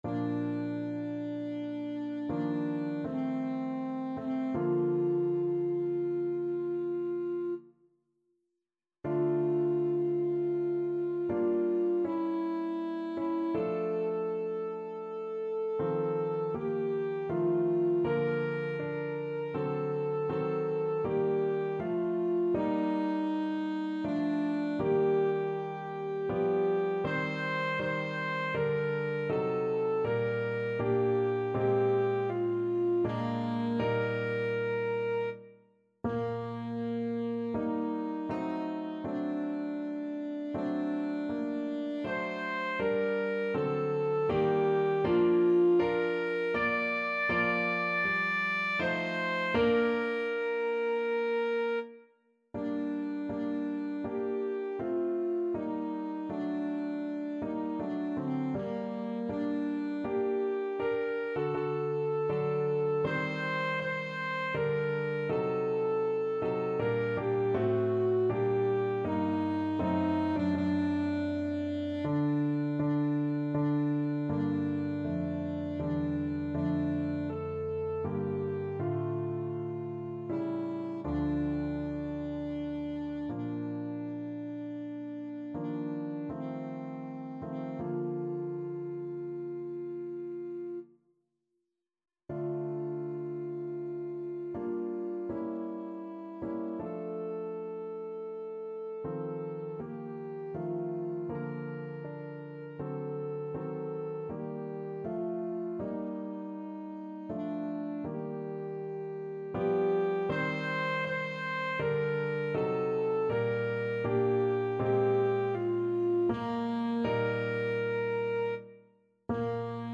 Alto Saxophone
~ = 80 Andante ma non lento
3/4 (View more 3/4 Music)
Classical (View more Classical Saxophone Music)